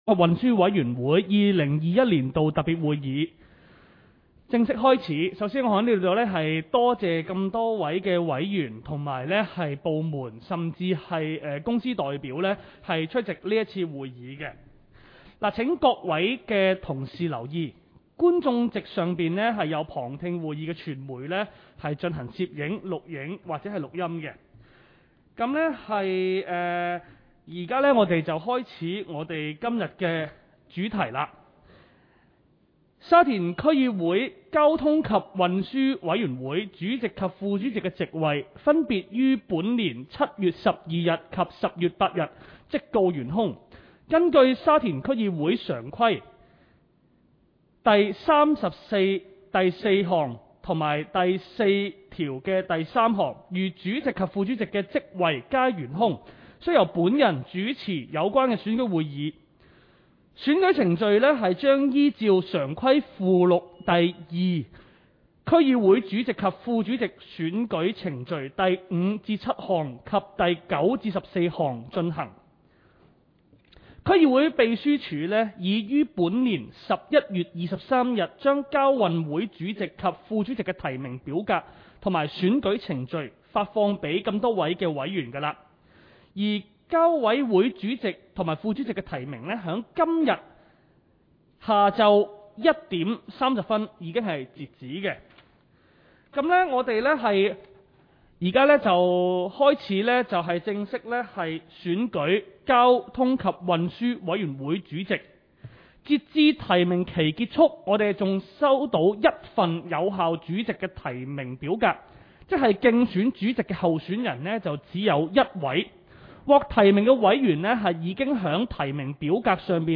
委员会会议的录音记录
地点: 沙田民政事务处 441 会议室